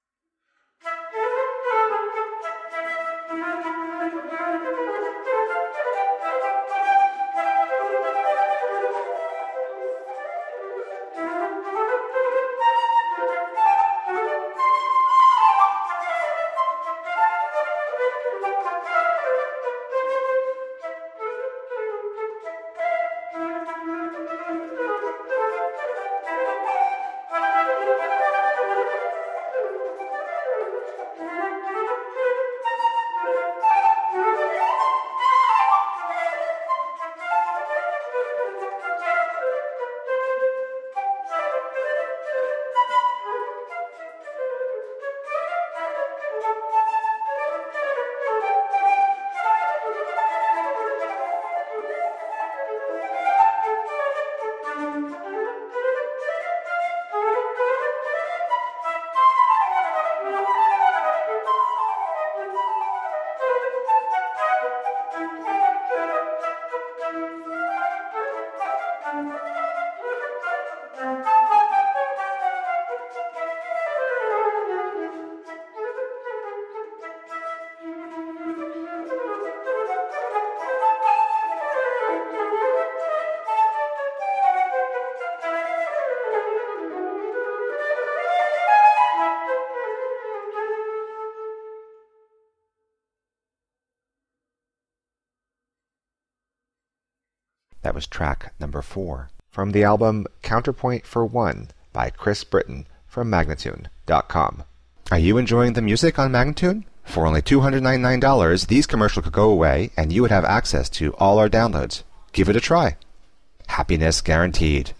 unaccompanied flute
dazzling and virtuosic transcriptions for solo flute